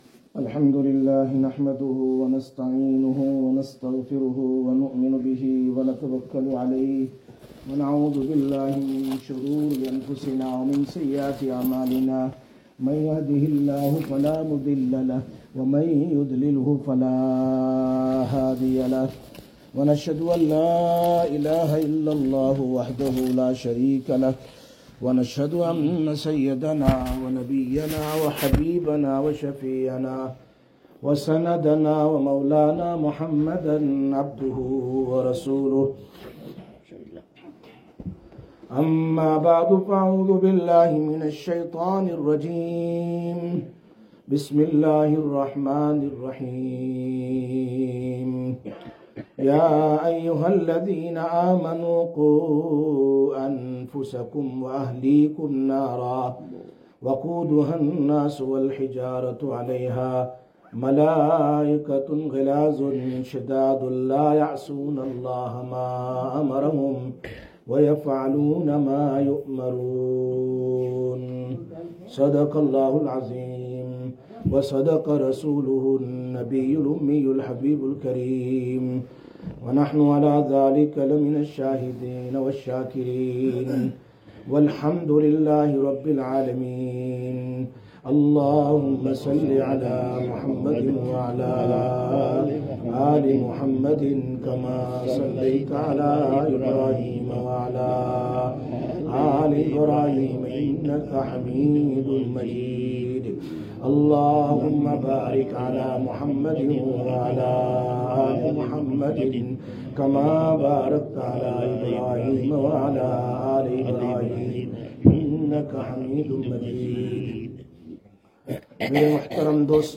12/05/2023 Jumma Bayan, Masjid Quba